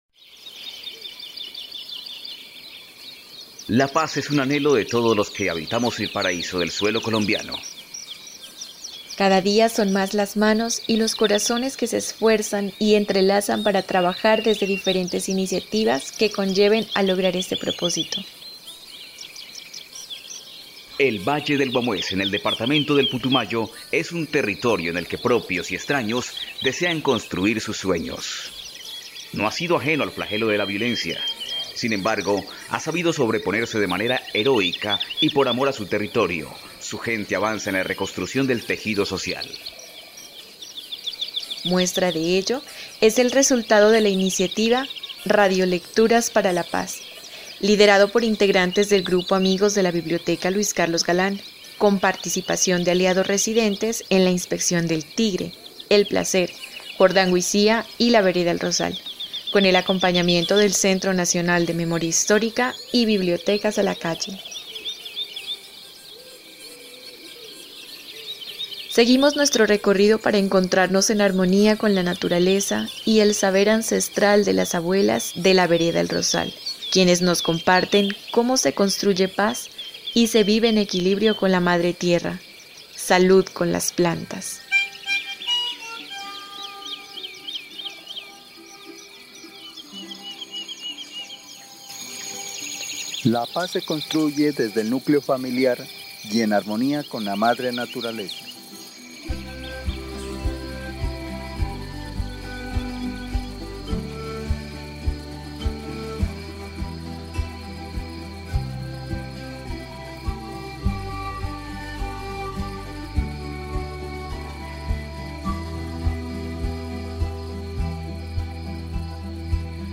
Resumen (dcterms:abstract) En el segundo episodio, “El Rosal”, habitantes de esta vereda describen cómo viven en equilibrio con la madre tierra o la casa común mediante la exposición de sus saberes sobre plantas medicinales nativas del territorio. En esta recopilación de voces diversas y acentos nativos, se narra cómo se construye paz y se vive en armonía con la naturaleza, así como se comparten conocimientos sobre plantas y remedios para distintas afecciones del cuerpo.